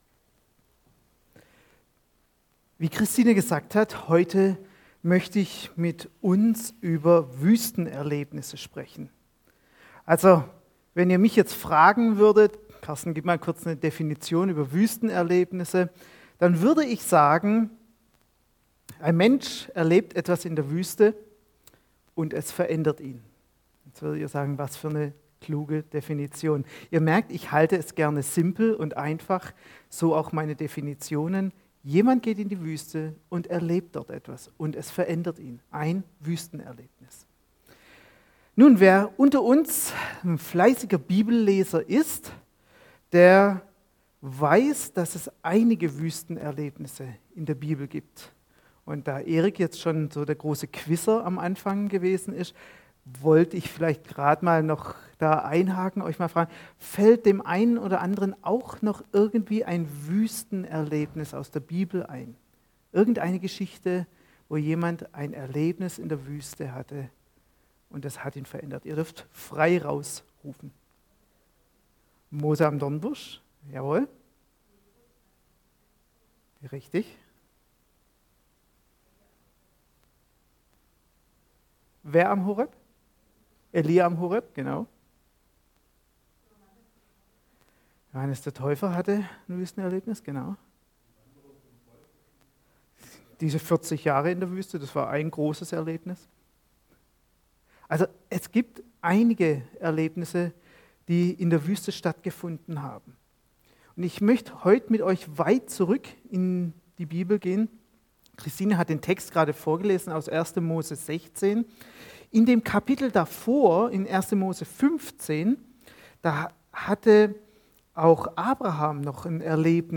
Predigt vom 02. Mai 2021 › ETG-Ludwigsburg